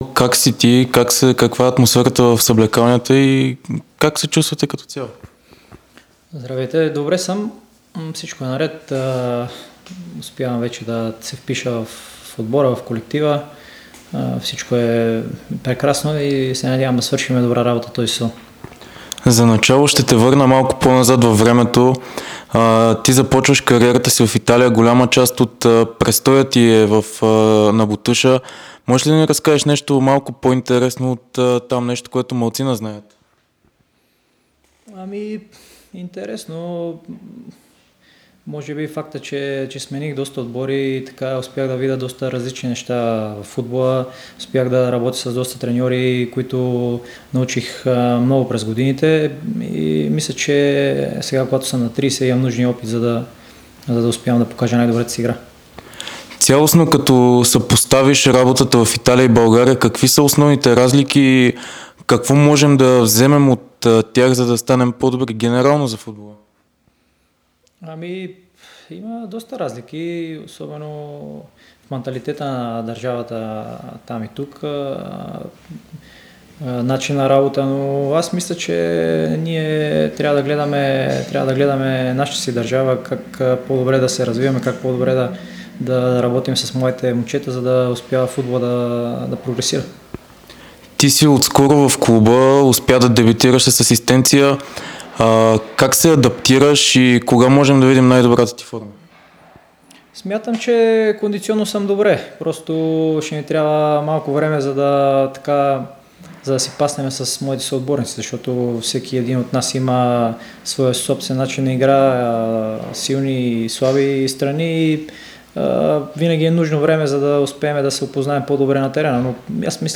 Нападателят на ЦСКА 1948 и националния отбор на България Радослав Кирилов даде специално интервю за dsport и Дарик радио. Той говори за началото на престоя си при „червените“ и предстоящите битки на „лъвовете“ през септември.